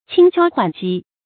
輕敲緩擊 注音： ㄑㄧㄥ ㄑㄧㄠ ㄏㄨㄢˇ ㄐㄧ 讀音讀法： 意思解釋： 比喻婉轉地表達意思和說明問題。